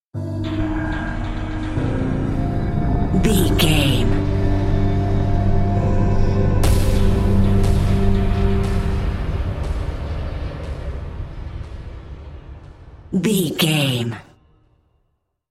Aeolian/Minor
synthesiser
percussion